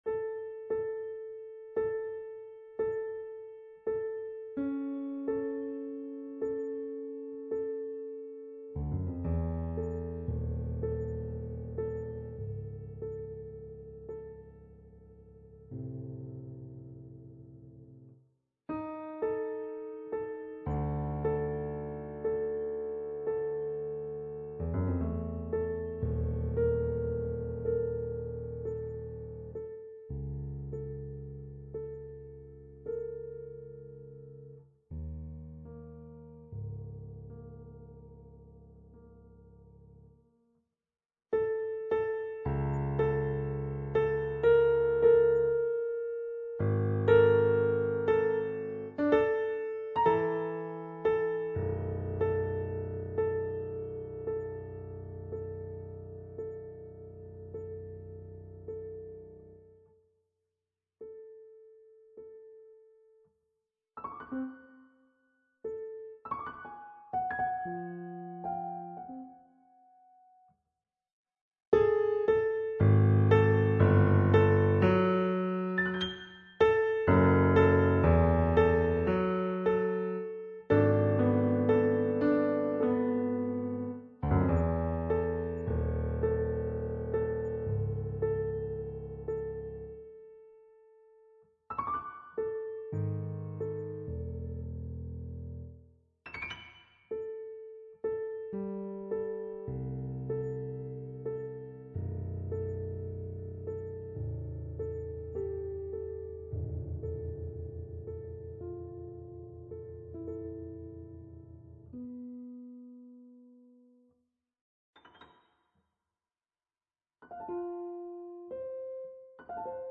Instrument(s): piano solo.